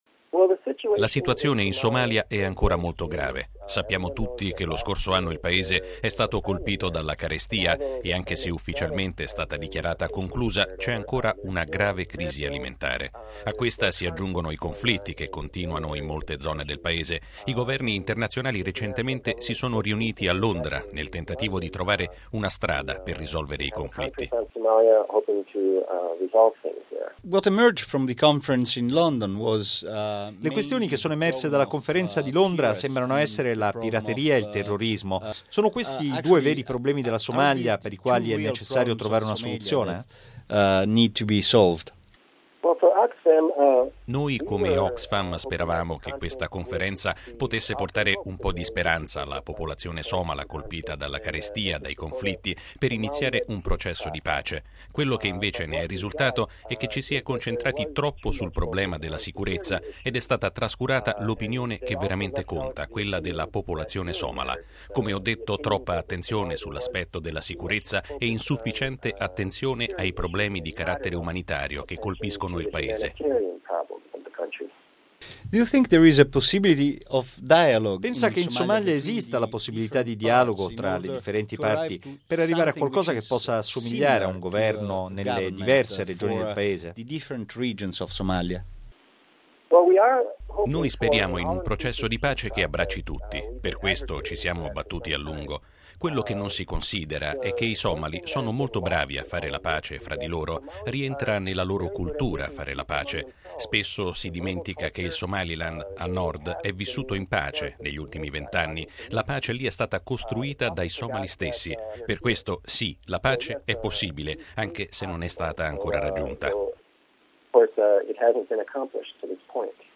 R. - The situation in Somalia is still very serious. …